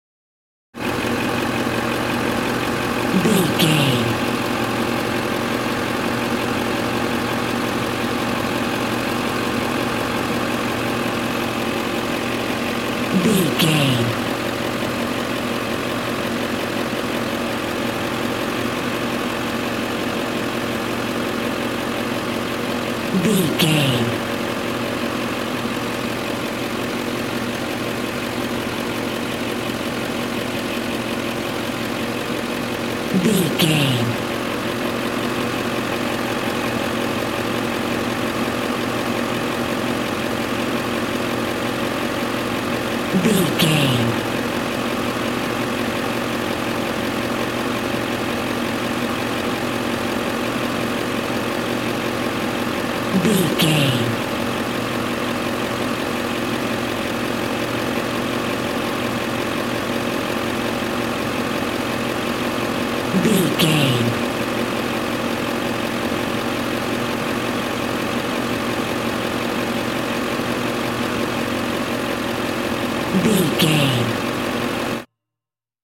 Ambulance Ext Diesel Engine Idle Close
Sound Effects
urban